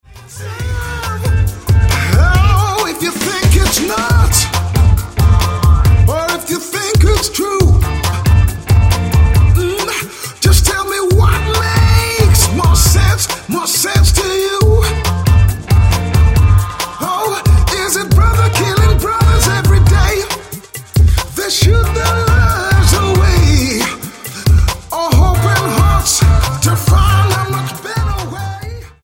Style: R&B Approach: Praise & Worship